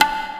soft-hitnormal2.mp3